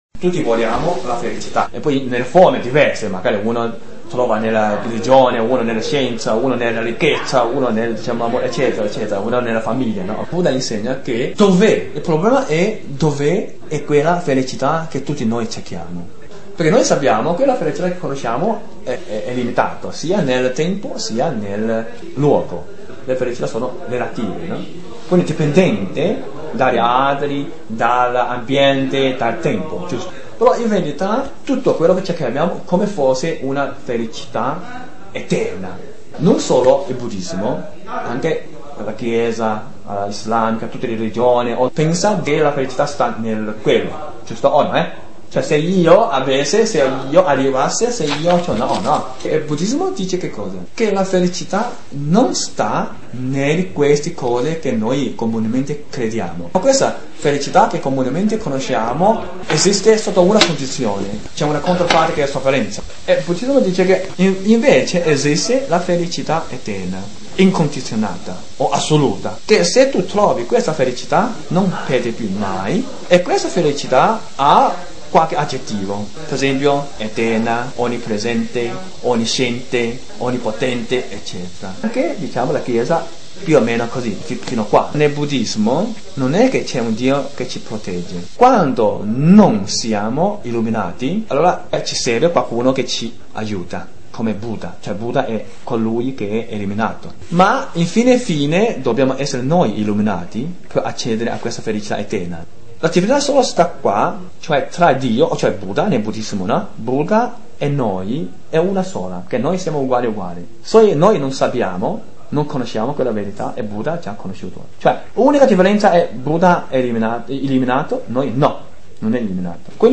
Interviste audio